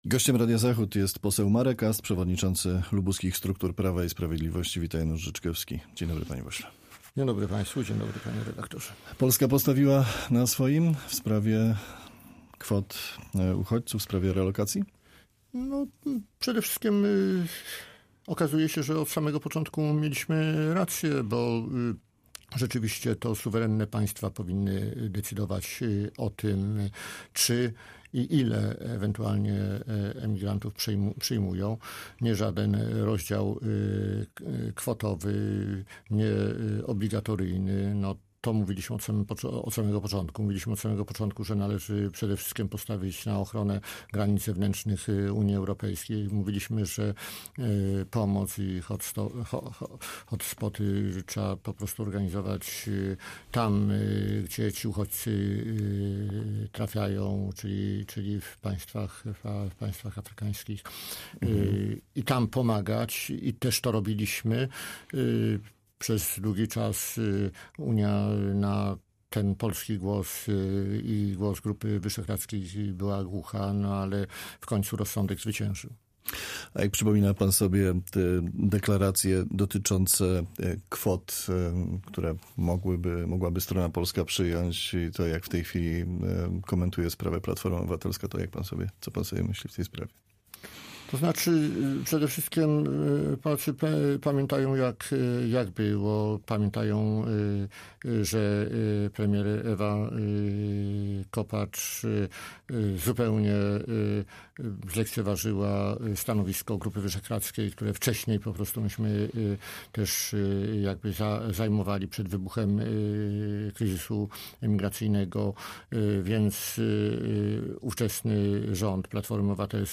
Z posłem PiS, przewodniczącym partii w województwie lubuskim rozmawia